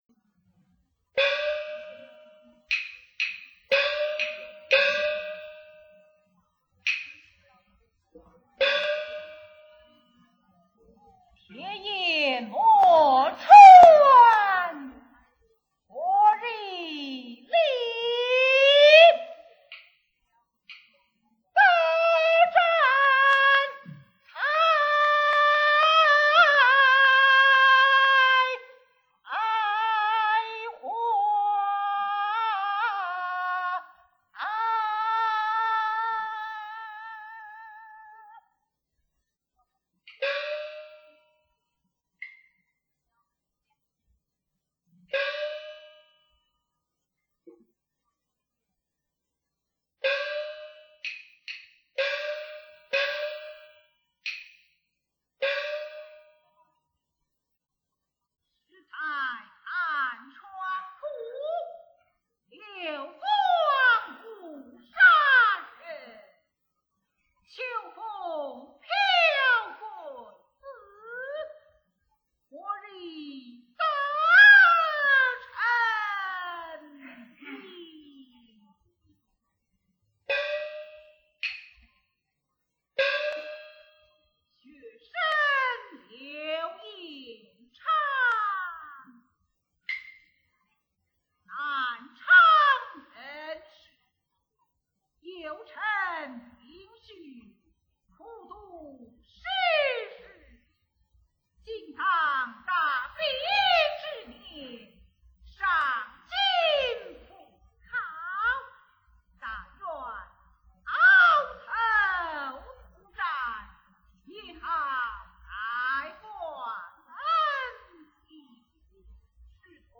1959年上海天蟾舞台实况选场
京剧